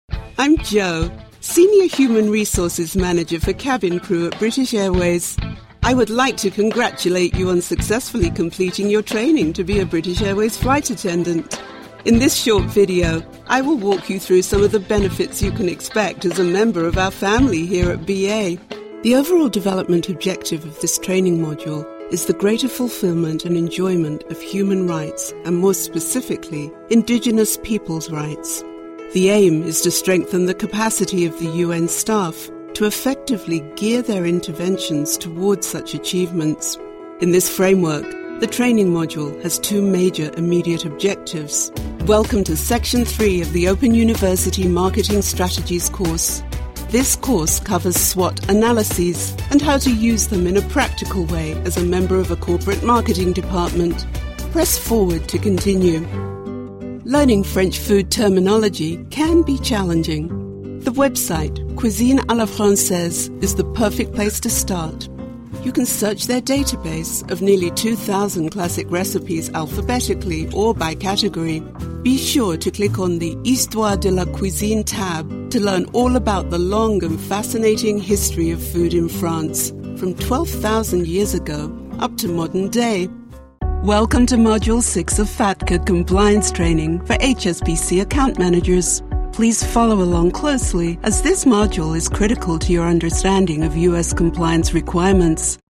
E-learning
I keep my delivery honest, believable, and authentic, and can also be humorous with a touch of irony and pizzazz when necessary.
TLM103 mic
ContraltoProfundoBajo
ConversacionalCálidoAmistosoGenuinoSofisticadoConfiableEntusiastaConfiadoConfidencialElocuenteInnovadorSerioCosmopolitaArtísticaCalmanteCreíble